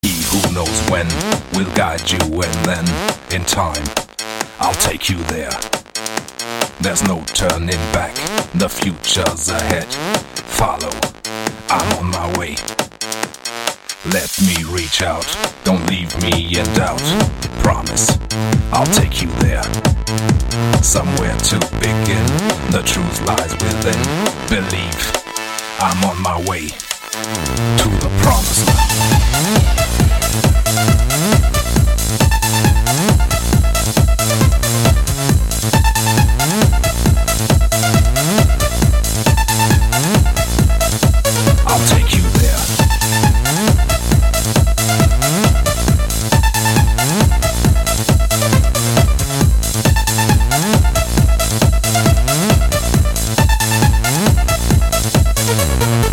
• Качество: 128, Stereo
громкие
Hard Trance
acid house
Клубный рингтончик)